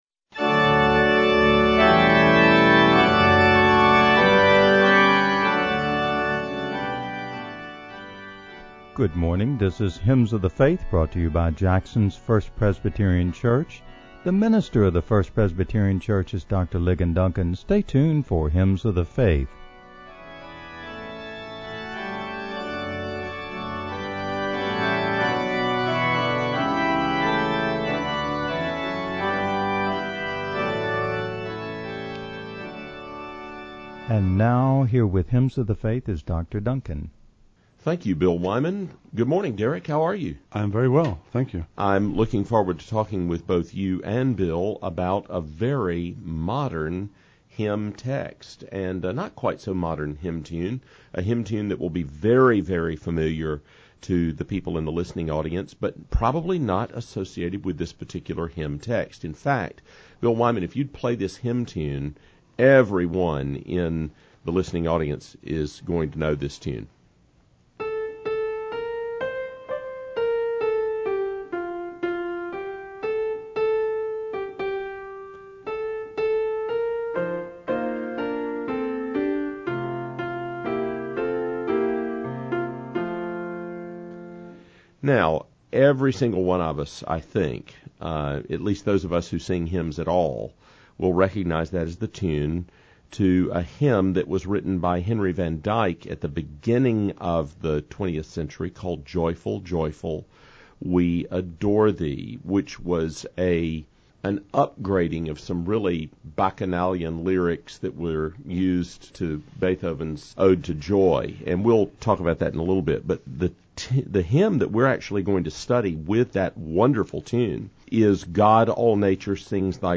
A Presentation of First Presbyterian Church Jackson, Mississippi